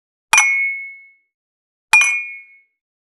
91.ワイングラス【無料効果音】
ASMRコップワイン
ASMR